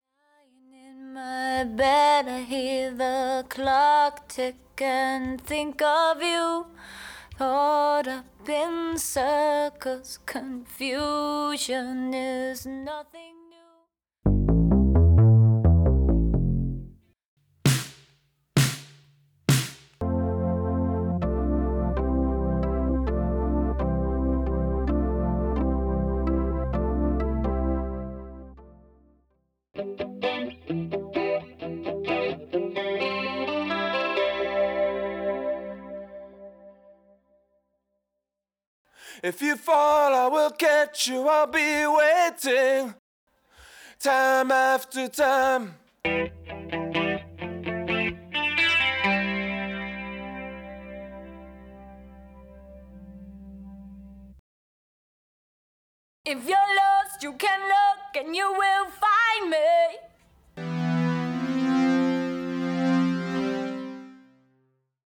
Studio Bassline Synth Stem
Studio Guitar Stem
Studio Organ Keys Stem
Studio Simmons Toms Stem
Studio Vocal Verse Stem